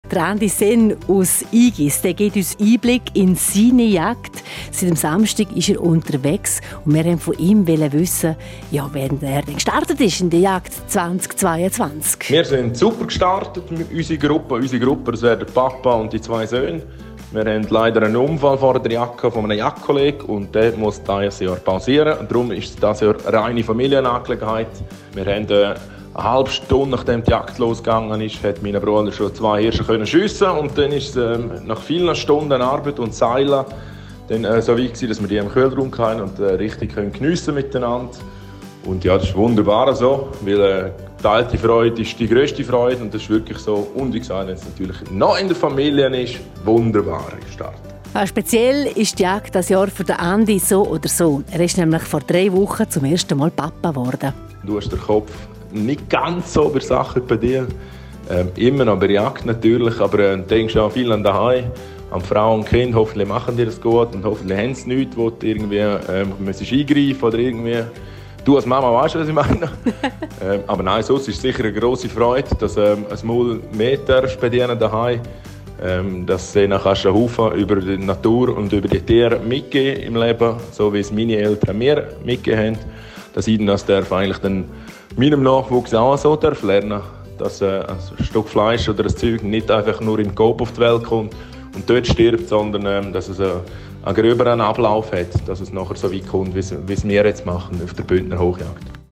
3Sendebeitrag.MP3